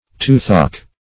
Toothache \Tooth"ache`\, n. (Med.)